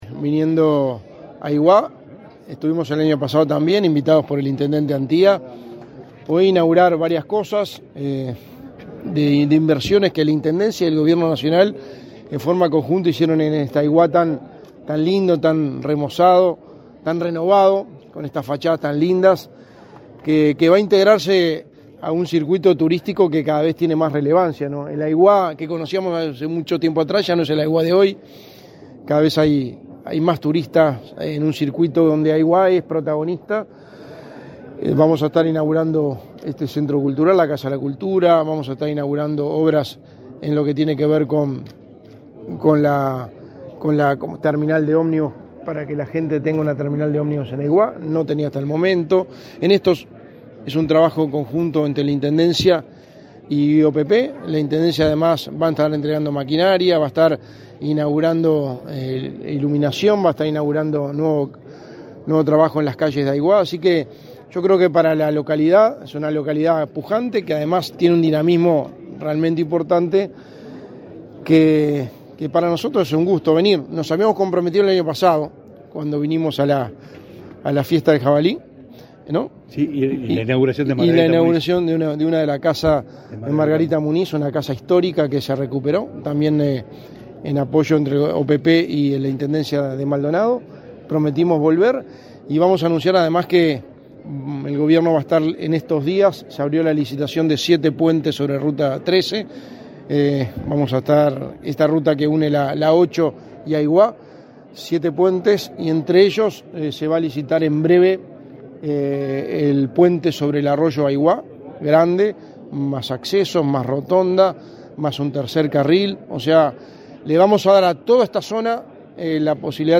Declaraciones a la prensa del secretario de la Presidencia, Álvaro Delgado
Declaraciones a la prensa del secretario de la Presidencia, Álvaro Delgado 22/05/2023 Compartir Facebook X Copiar enlace WhatsApp LinkedIn Tras participar en la inauguración de obras en Aiguá, en el marco del 117.° aniversario de la localidad, este 22 de mayo, el secretario de la Presidencia, Álvaro Delgado, realizó declaraciones a la prensa.